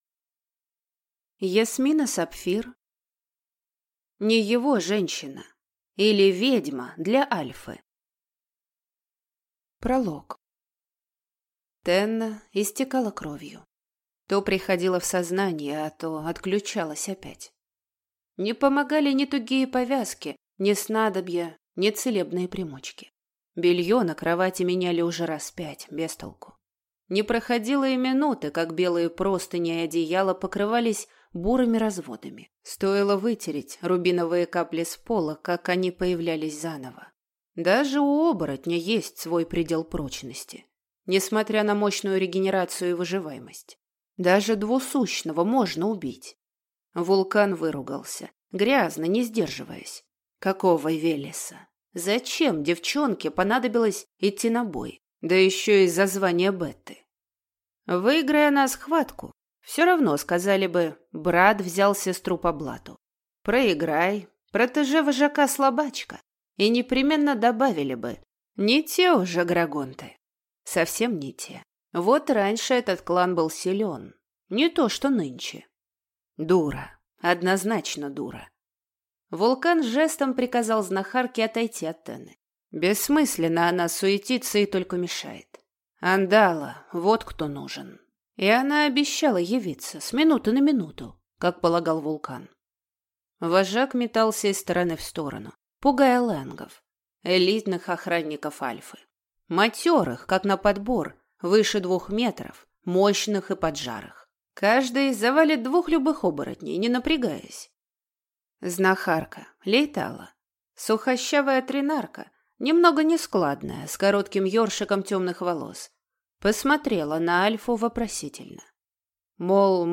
Аудиокнига (Не) его женщина, или Ведьма для альфы | Библиотека аудиокниг
Прослушать и бесплатно скачать фрагмент аудиокниги